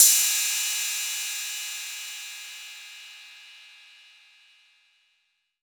808CY_5_Orig.wav